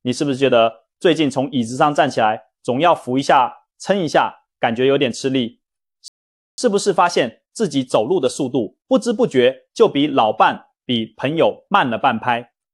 通过专为医疗解说、健康提示和营养指导优化的值得信赖、表达清晰的AI语音吸引您的观众。
文本转语音
平静语调
我们的AI提供可靠、权威的语调，这对健康和健身内容至关重要。
通过分析专业医疗解说员的语音打造，这个语音保持稳定、令人安心的节奏。